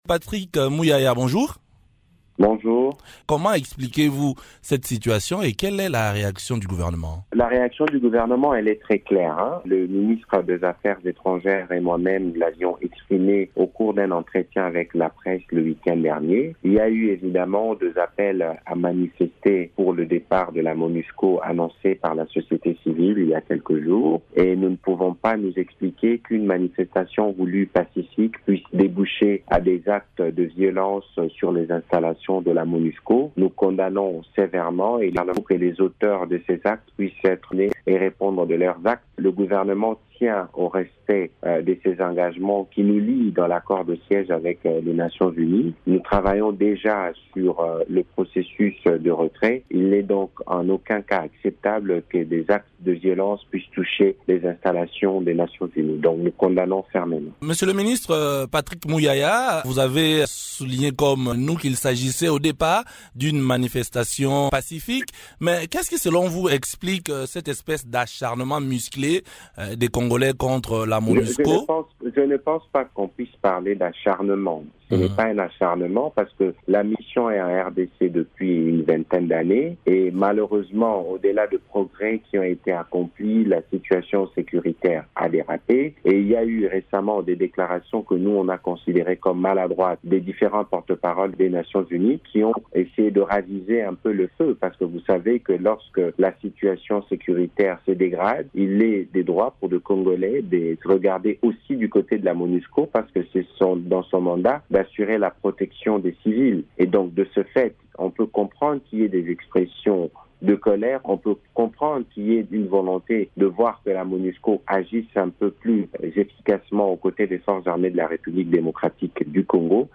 Patrick Muyaya, ministre de la Communication et médias et porte-parole du gouvernement réagit aux manifestations contre la MONUSCO organisées notamment lundi 25 juillet à Goma, dans la province du Nord-Kivu. Il condamne les actes de pillage et les attaques contre les installations de la MONUSCO.